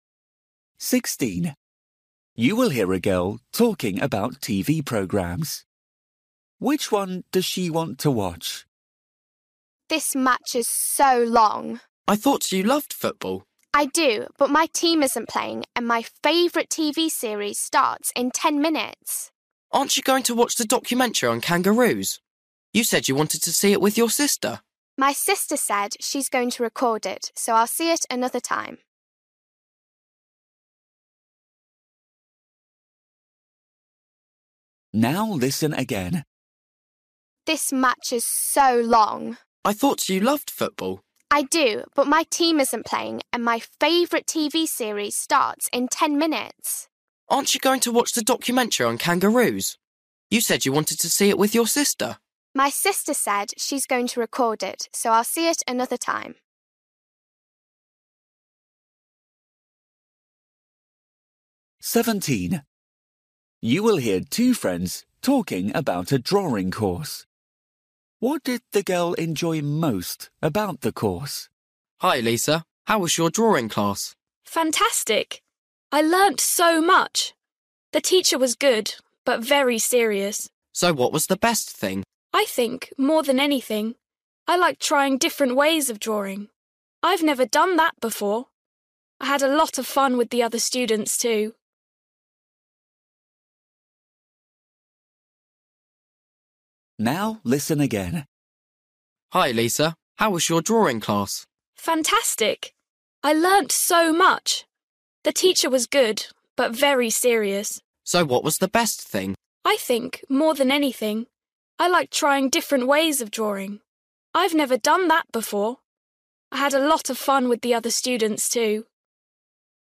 Listening: everyday short conversations
16   You will hear a girl talking about TV programmes. Which one does she want to watch?
17   You will hear two friends talking about a drawing course. What did the girl enjoy most about the course?
19   You will hear a boy talking about a day out with his grandparents. Why did they go to the lake?